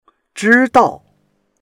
zhi1dao4.mp3